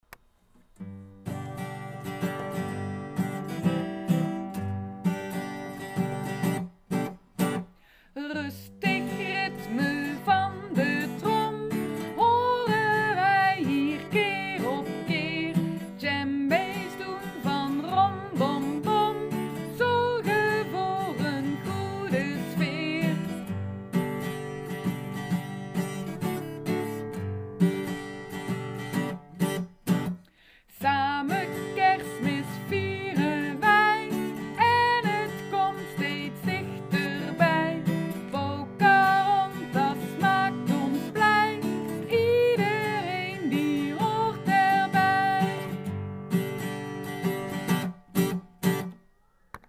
liedjes voor de kerstviering